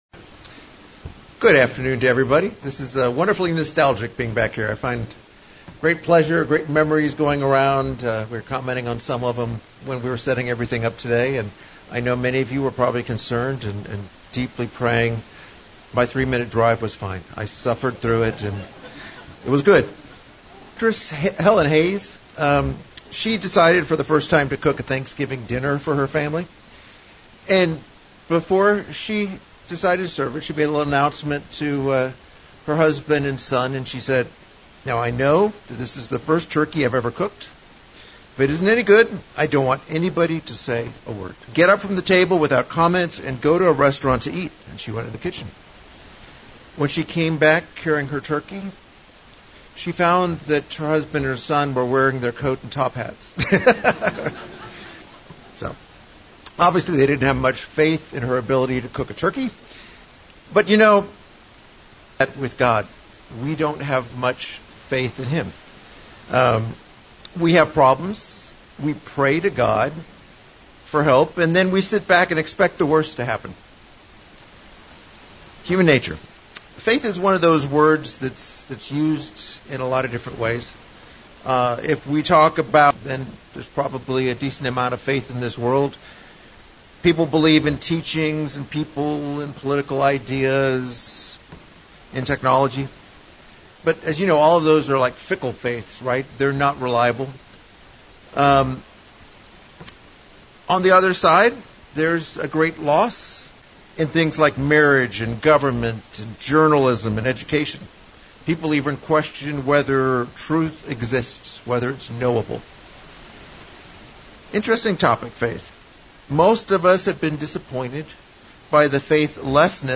This sermon reviews multiple lessons from Jesus on how this is achieved and examples of those who display great faith.